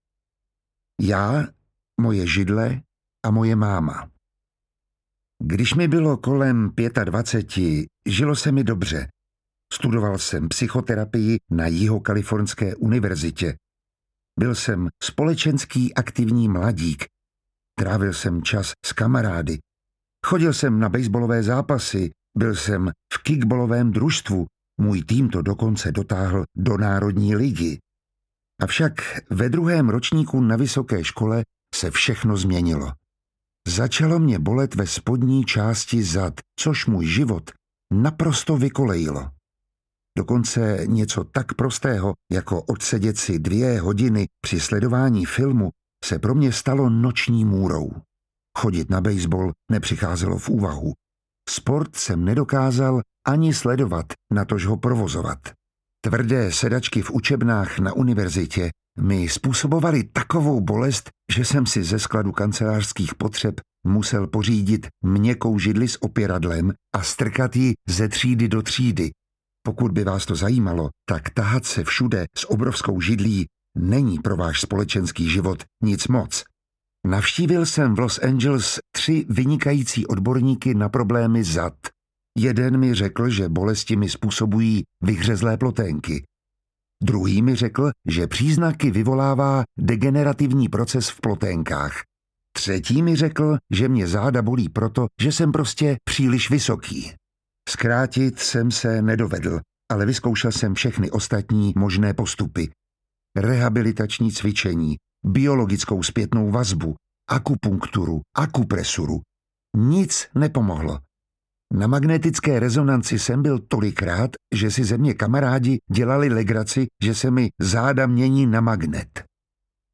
audiokniha
Čte: Miroslav Táborský